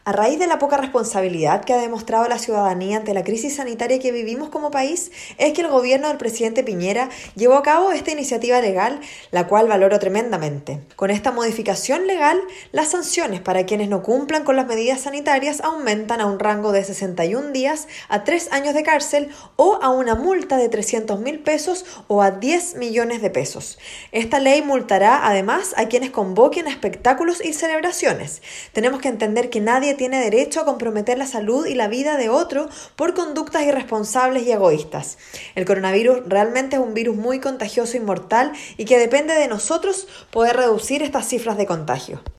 Cuña-Pía-Bersezio-seremi-de-Gobierno-por-promulgación-ley-aumento-sanciones-por-incumplir-medidas-sanitarias.mp3